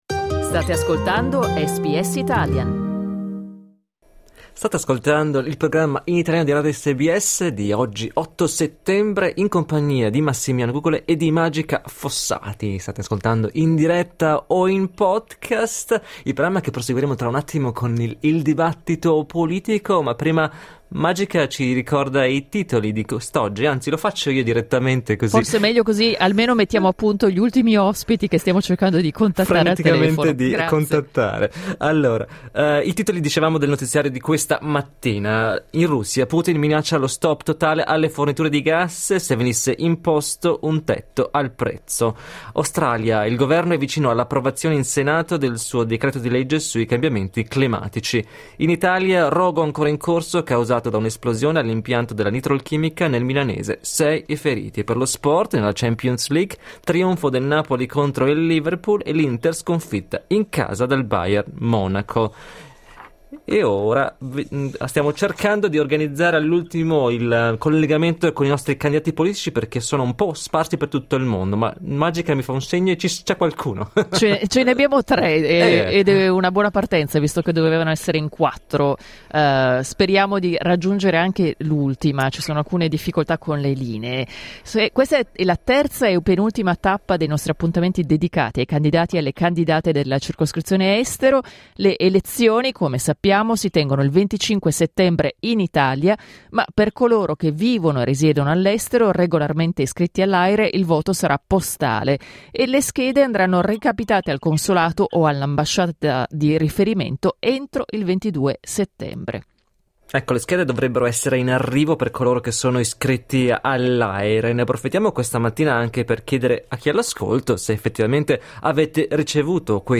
Gli ospiti di SBS Italian per il terzo dibattito elettorale